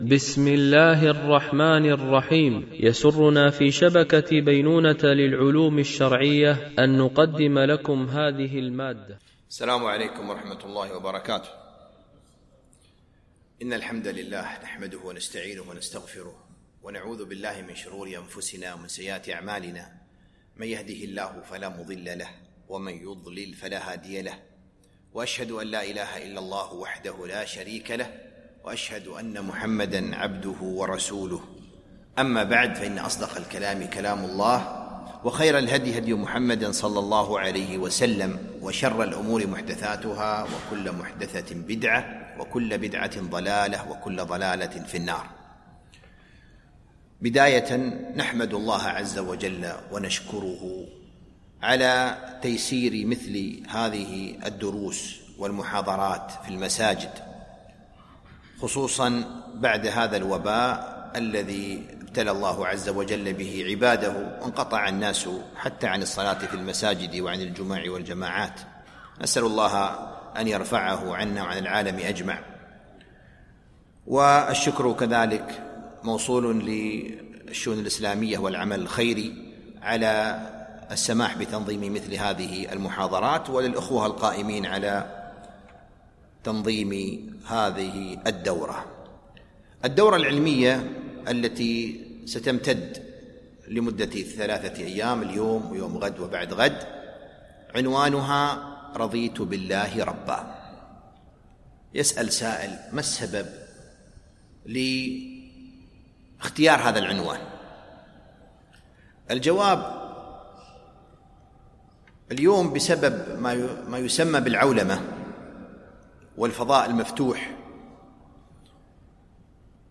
دورة علمية
مسجد الورقاء الكبير - دبي